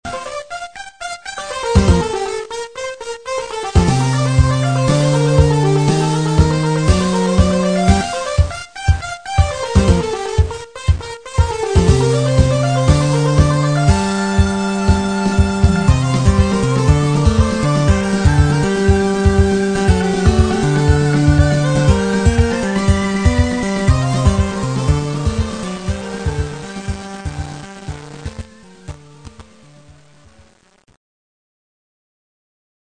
まだまだ製作途中なのでしょぼい（メロと簡単なリズムしか入れてない）ですが、
音質やエンコーディングが荒っぽいですが。
ムボーにもラテンに挑戦してみたんですが、中途半端なディスコ風になってるという噂もあります（爆）。
音色に関してはウチのPC＆シーケンスソフトがしょぼいのでこんなですが、